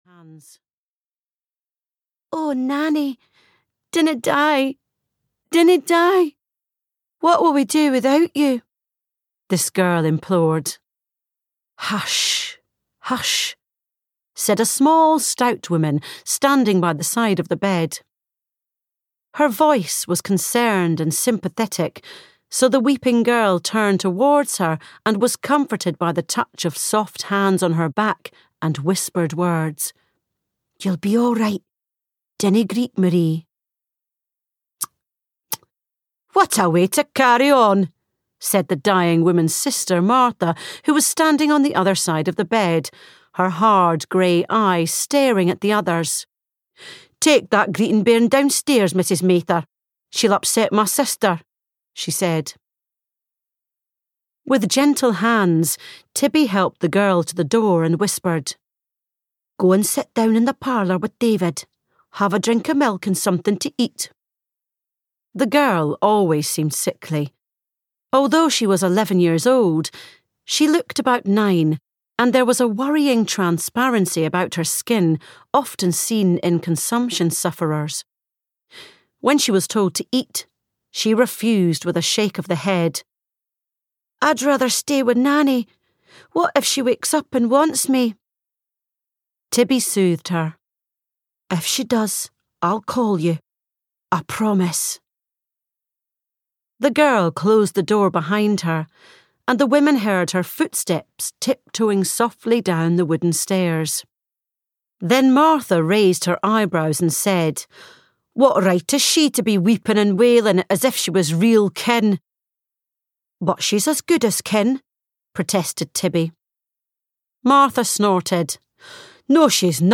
Wild Heritage (EN) audiokniha
Ukázka z knihy